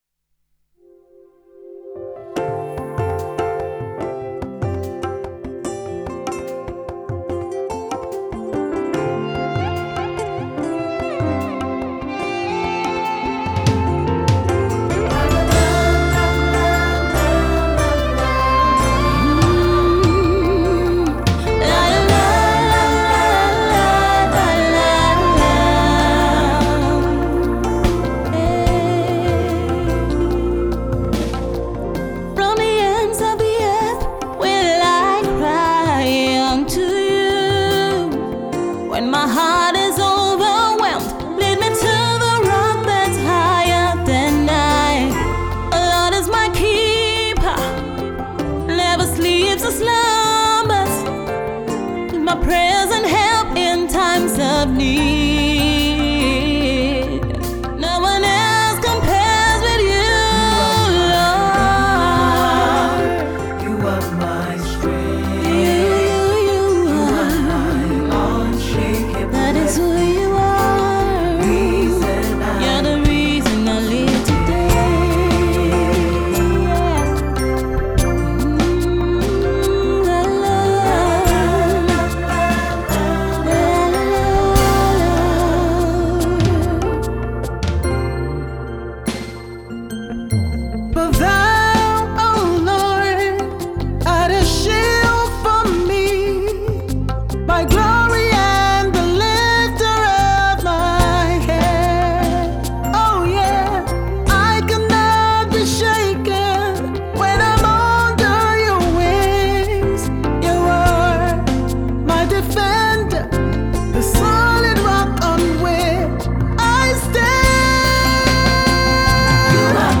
Gospel
an essential praise & worship tool